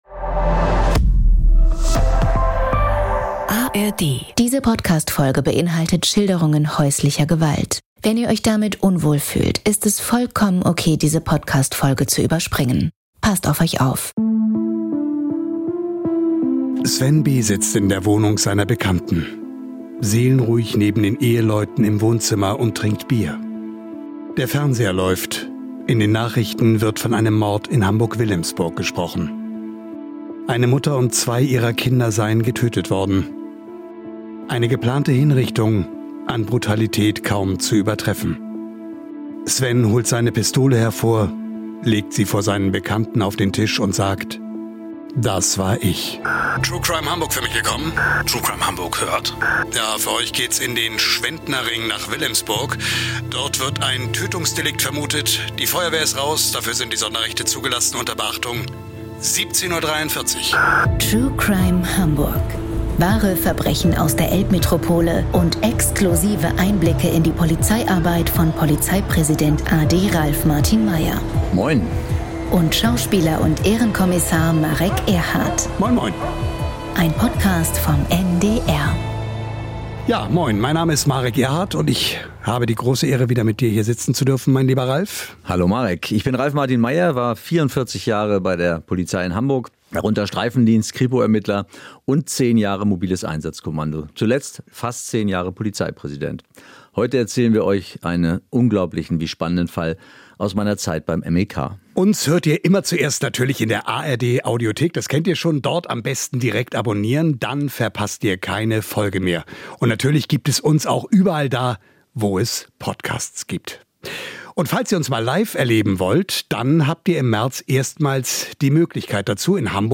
Im Gespräch mit Schauspieler Marek Erhardt erzählt Hamburgs Polizeipräsident a.D. Ralf Martin Meyer, wie auf eine geplante Hinrichtung eine Geiselnahme folgte und professionelles Handeln in einer Extremlage weiteres Blutvergießen verhindern konnte.